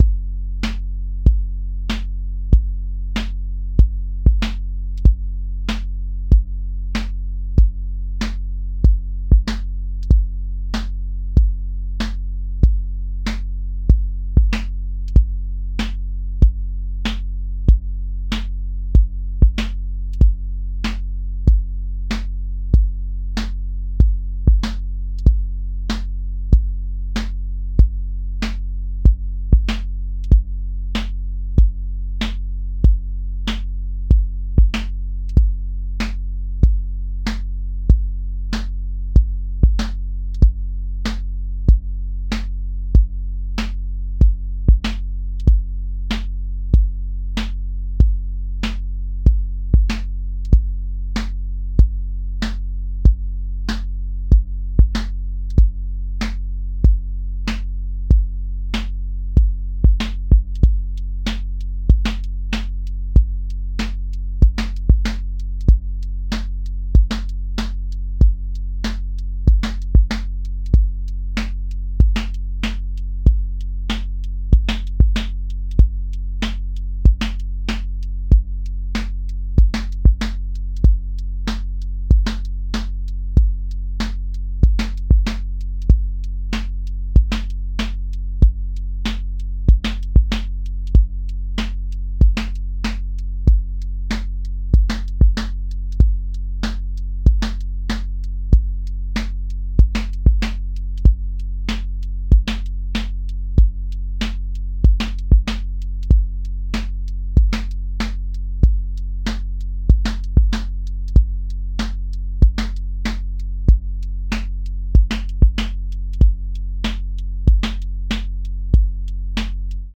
• tone_warm_body